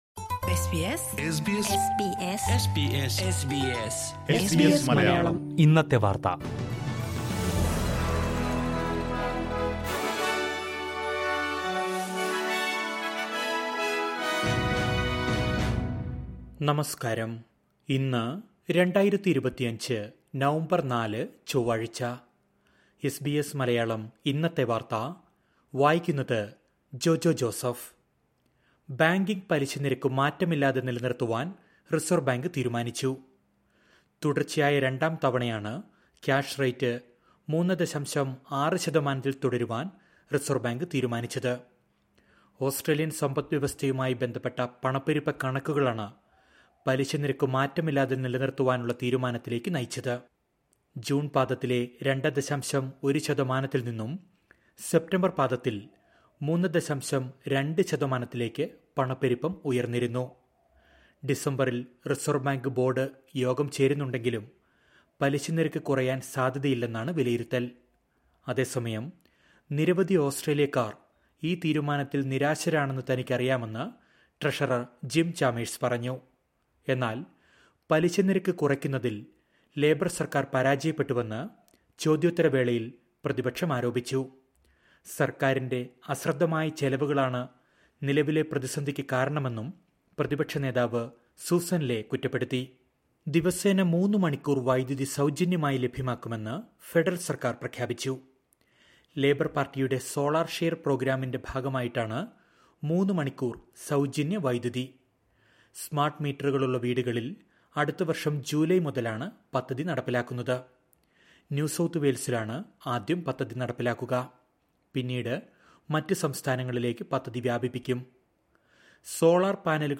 2025 നവംബർ നാലിലെ ഓസ്ട്രേലിയയിലെ ഏറ്റവും പ്രധാന വാർത്തകൾ കേൾക്കാം...